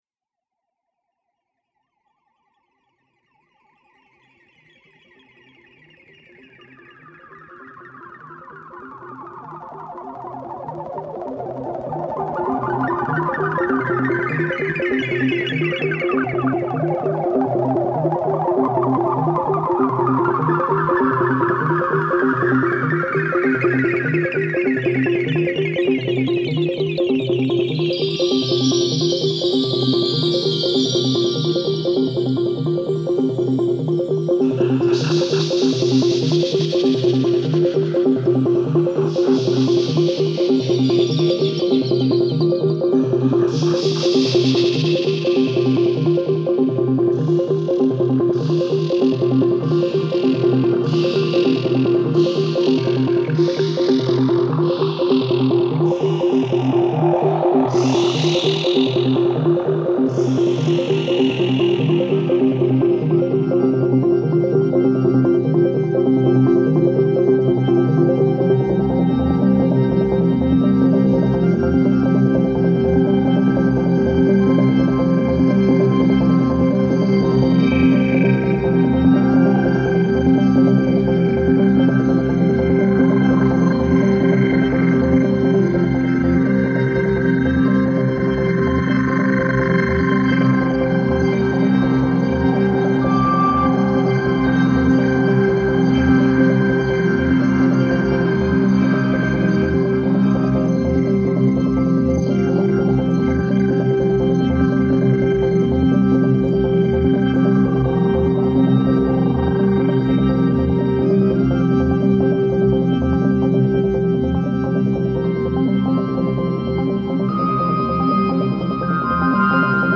it’s a deep electronic dub trip